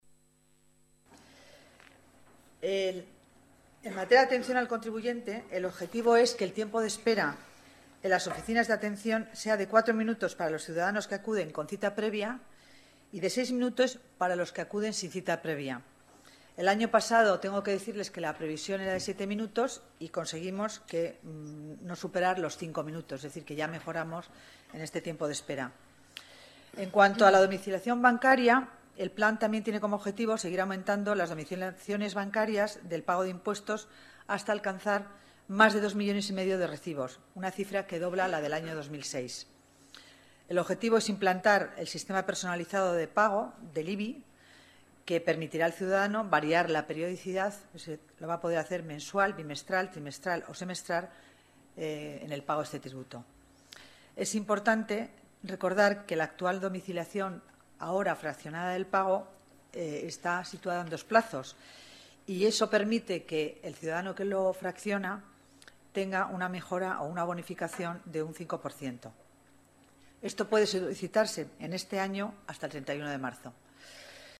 Nueva ventana:Declaraciones de la delegada de Hacienda, Concepción Dancausa